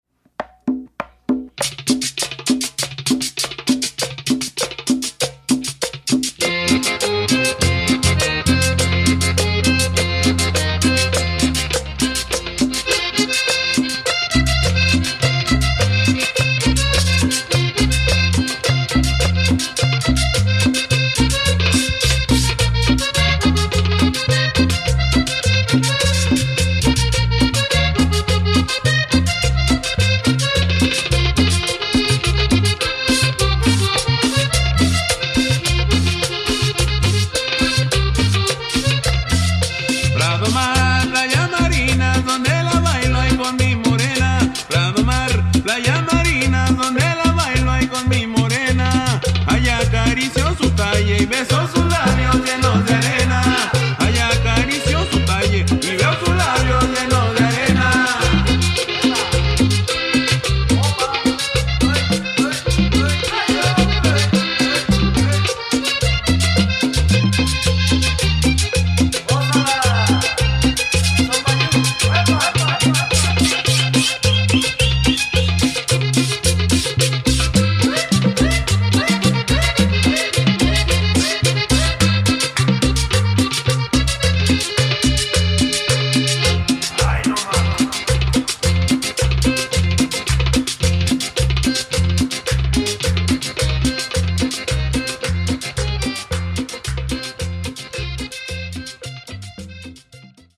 Tags: Cumbia